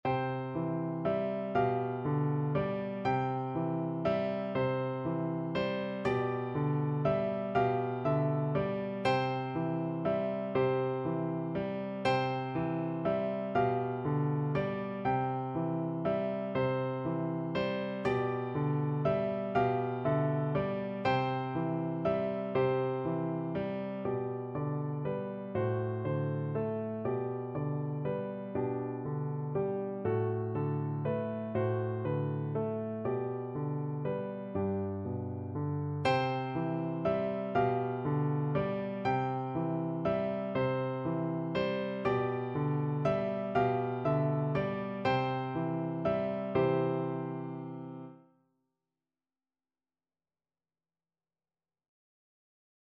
Traditional Trad. Girls and Boys Come Out to Play (English Traditional) Piano Four Hands (Piano Duet) version
3/4 (View more 3/4 Music)
C major (Sounding Pitch) (View more C major Music for Piano Duet )
Brightly = c. 120
Traditional (View more Traditional Piano Duet Music)